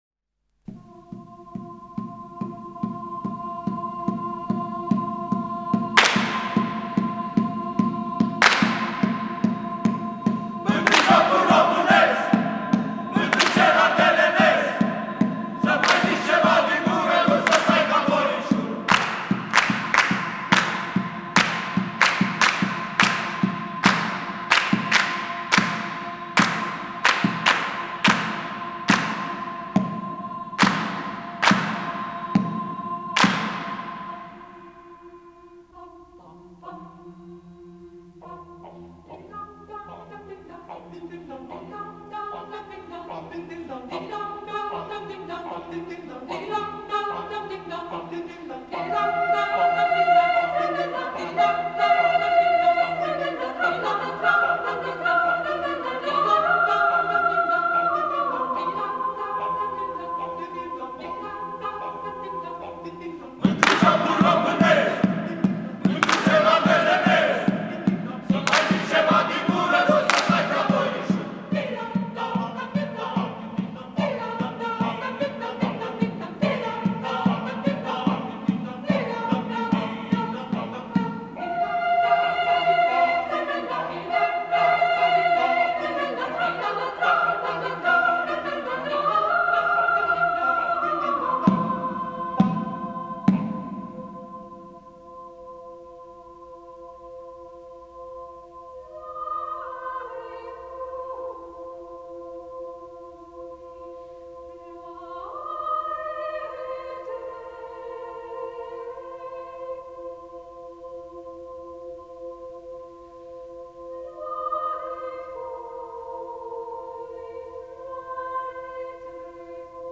Divertisment folcloric